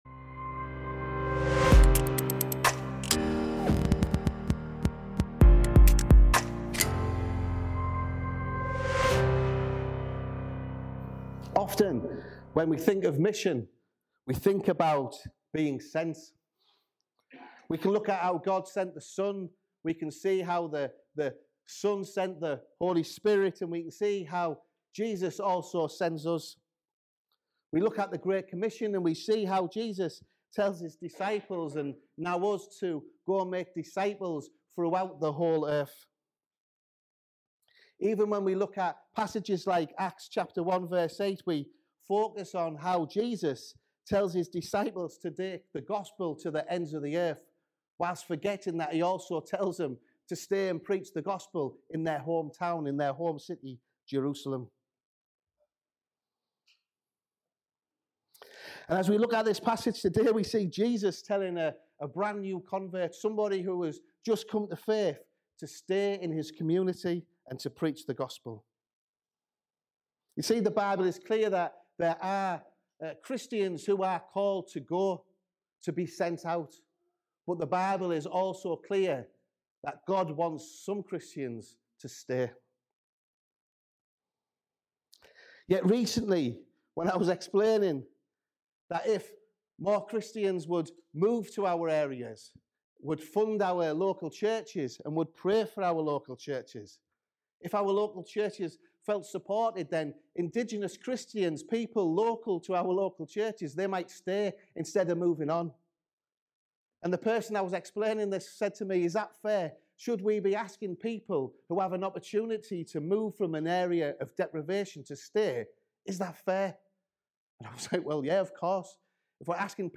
at Session 1 of the Medhurst Ministries 2020 Weekender